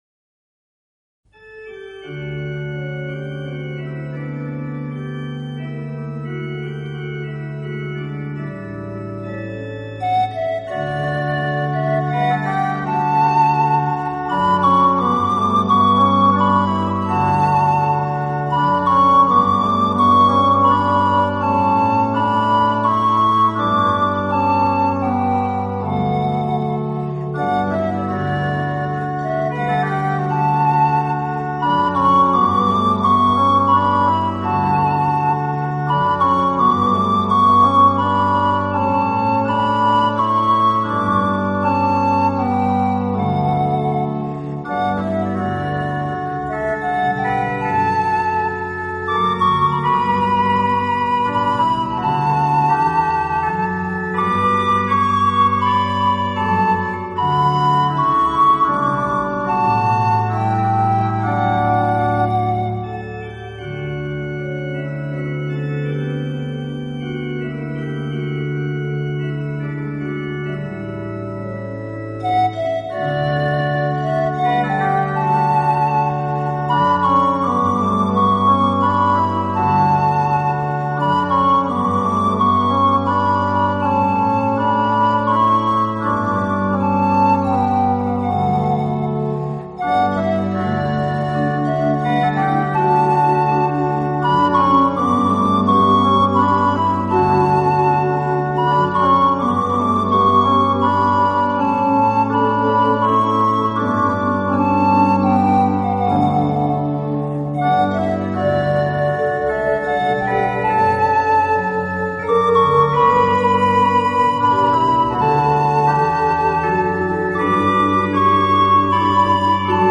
【圣诞排箫】
音乐流派Genre......: Instrumental